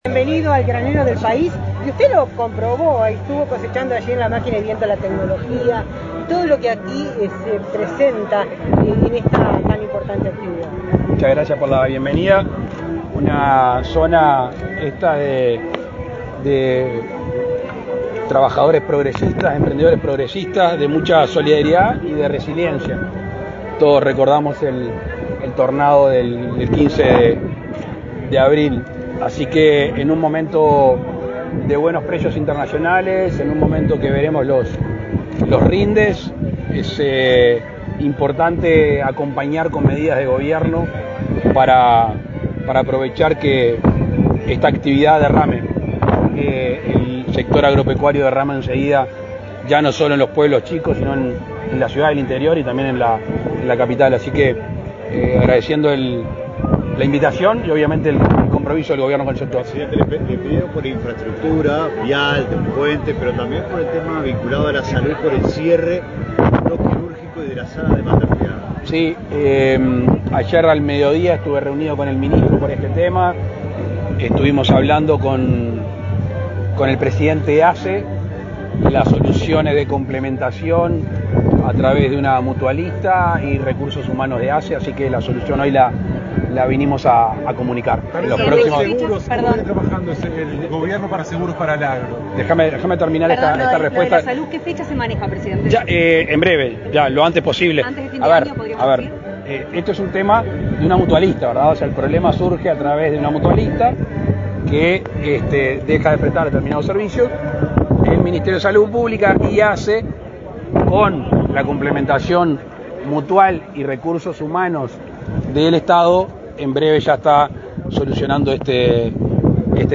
Declaraciones del presidente Lacalle Pou a la prensa
El presidente Luis Lacalle Pou encabezó este martes 16 en Dolores, Soriano, la inauguración de la cosecha de trigo, ceremonia convocada por la